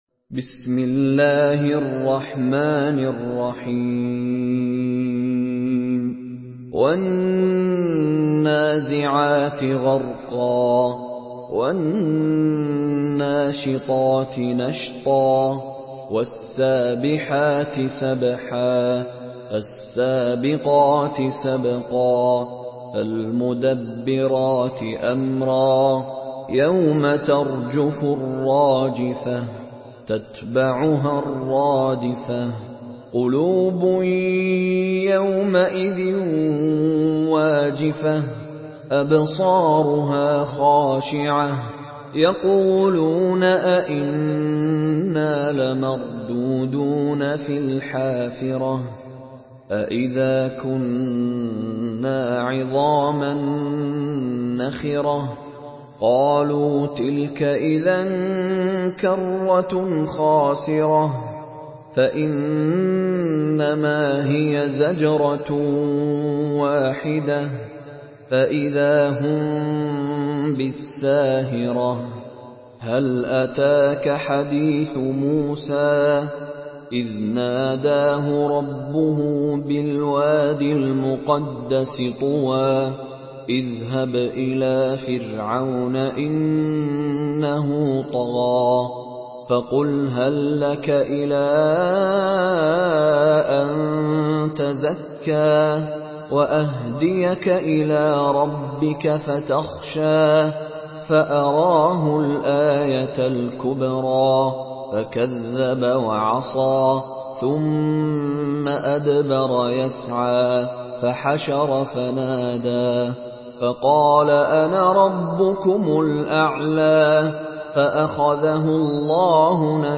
Surah Naziat MP3 by Mishary Rashid Alafasy in Hafs An Asim narration.
Murattal